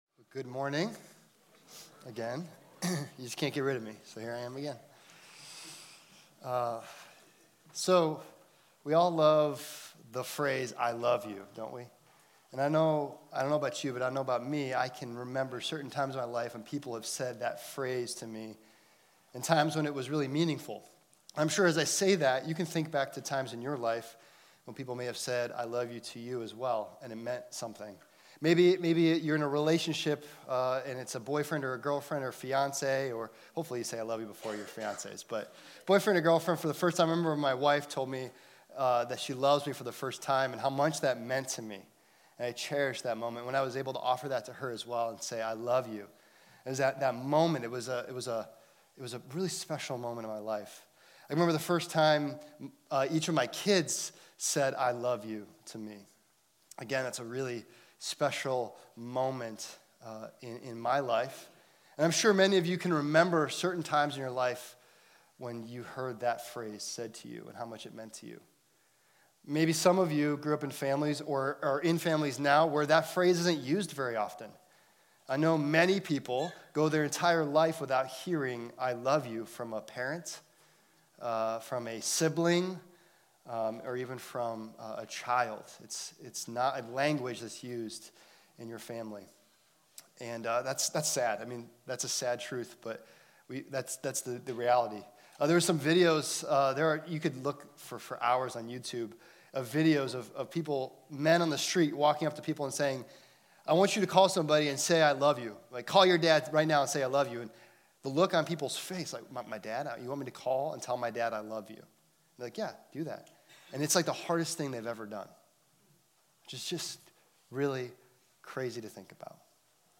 Loving the Inner Circle - Week Three (Avon Lake Campus)